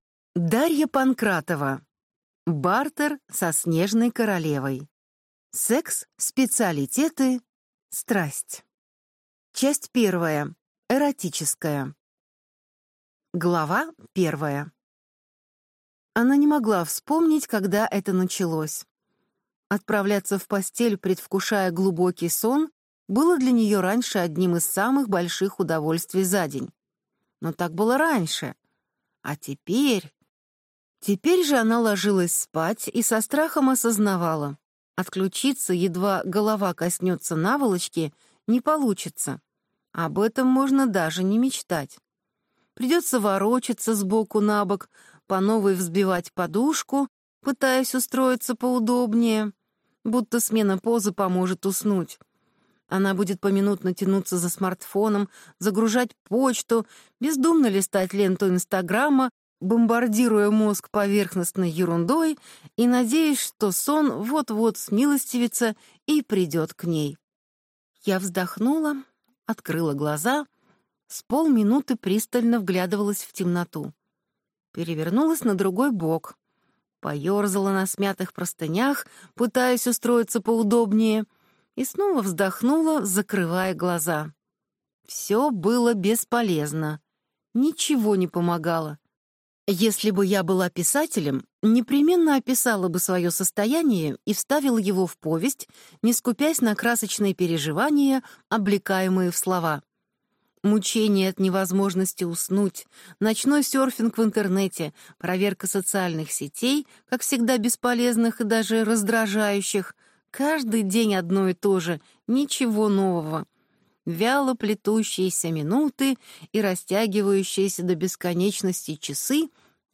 Аудиокнига Бартер со Снежной королевой | Библиотека аудиокниг